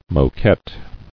[mo·quette]